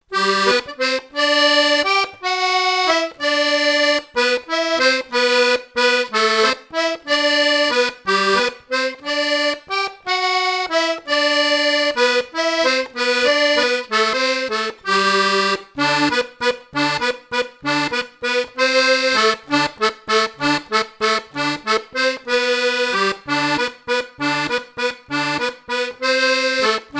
Mémoires et Patrimoines vivants - RaddO est une base de données d'archives iconographiques et sonores.
danse : valse
Répertoire à l'accordéon diatonique
Pièce musicale inédite